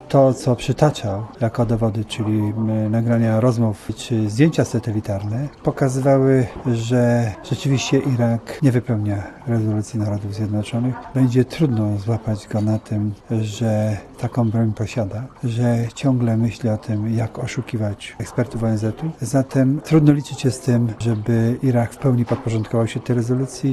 Wystąpienie Colina Powella było bardzo przekonywujące i spójne - mówi wieloletni oficer wywiadu generał Gromosław Czempiński.
Komentarz audio